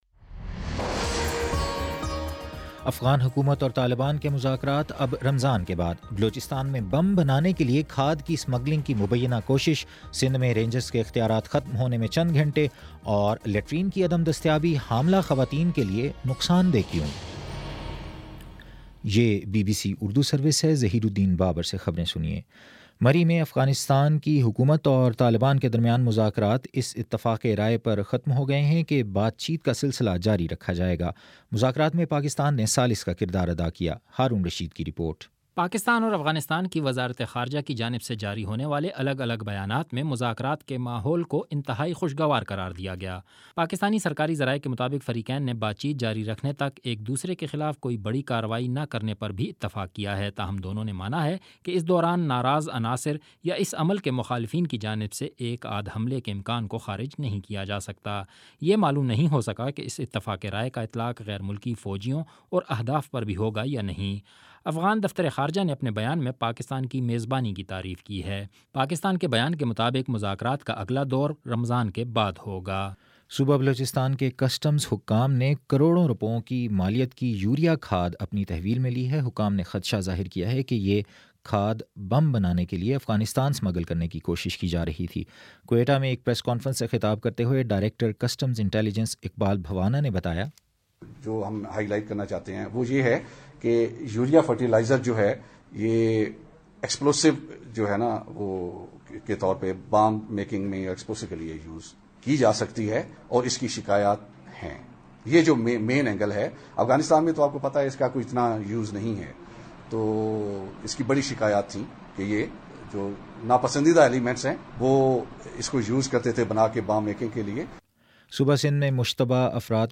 جولائی 8: شام چھ بجے کا نیوز بُلیٹن